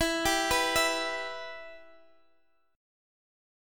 Em Chord
Listen to Em strummed